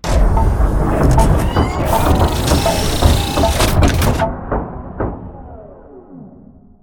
repair.ogg